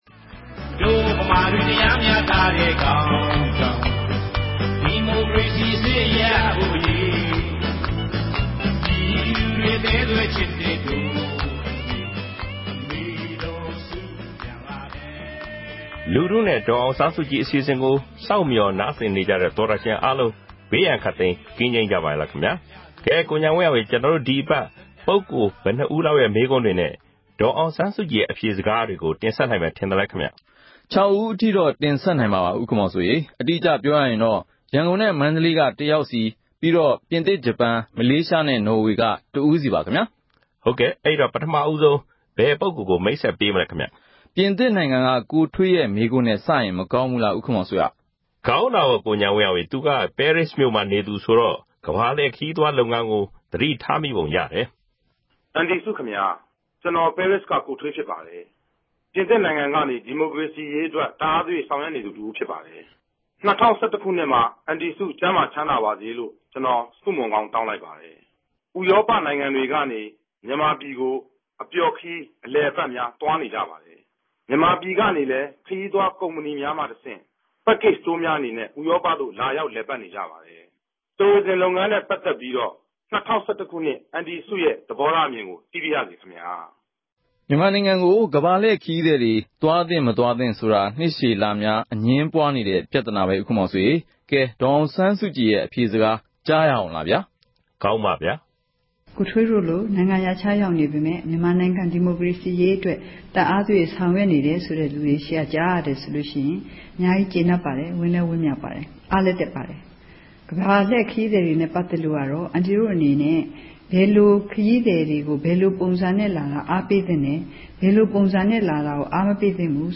အဲဒီ တယ်လီဖုန်း နံပါတ်ကို RFA က ဆက်သွယ်ပြီး ကာယကံရှင်ရဲ့ မေးမြန်းစကားတွေကို အသံဖမ်းယူကာ ဒေါ်အောင်ဆန်းစုကြည်ရဲ့ ဖြေကြားချက်နဲ့အတူ ထုတ်လွှင့်ပေးမှာ ဖြစ်ပါတယ်။